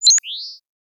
Holographic UI Sounds 90.wav